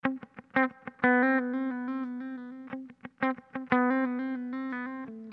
Sons et loops gratuits de guitares rythmiques 100bpm
Guitare rythmique 63